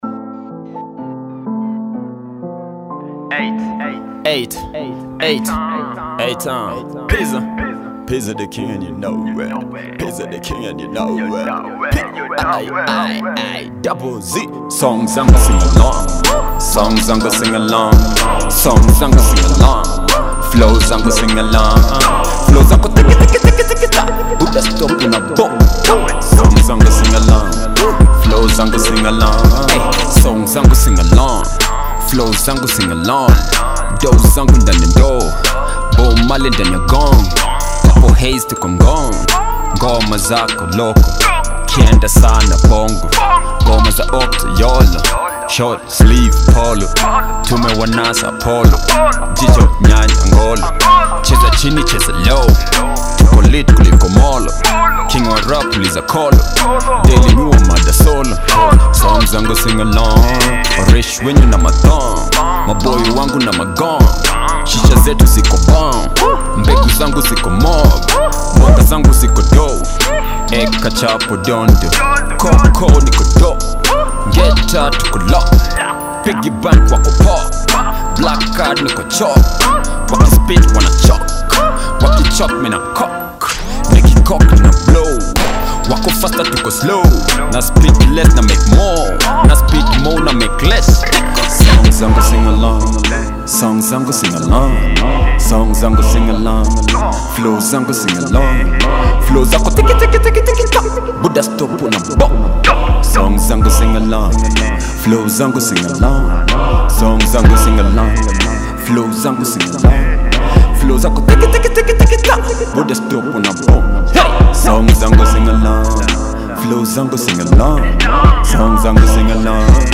bongo / hiphop